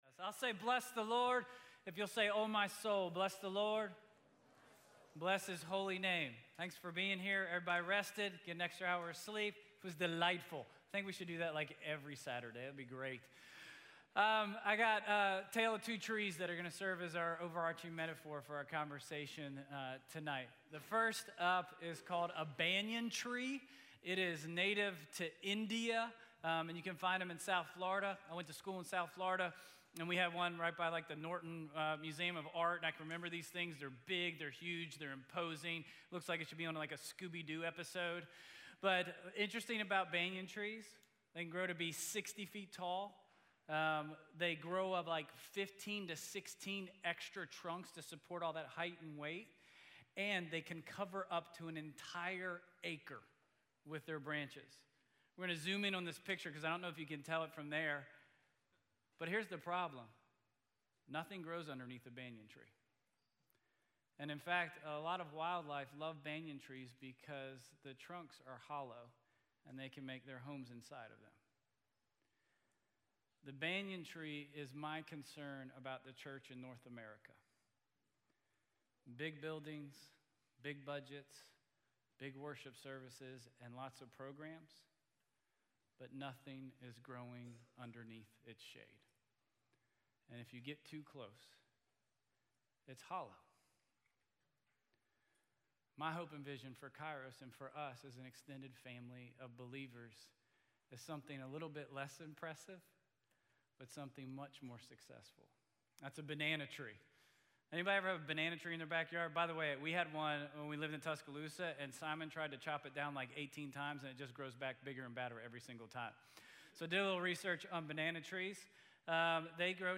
Sermon Audio
KAIROS+11-01+Sermon+Only+Audio.mp3